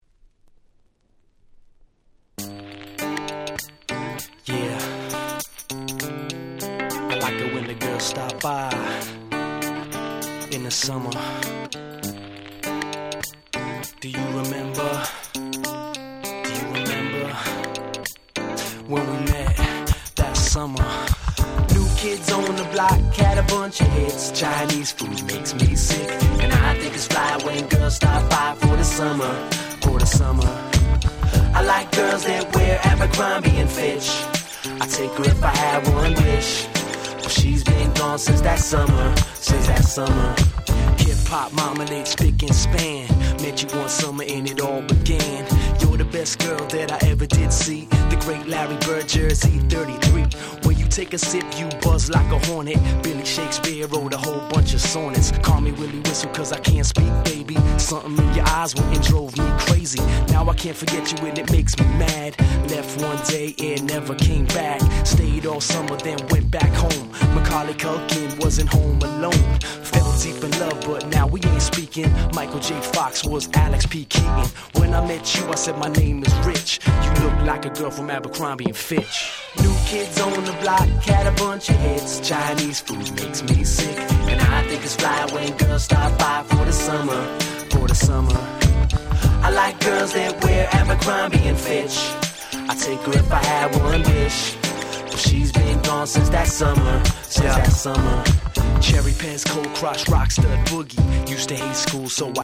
99' Nice Dance Pop / R&B !!
USの人気男性アイドルグループによるSmash Hit !!